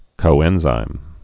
(kō-ĕnzīm)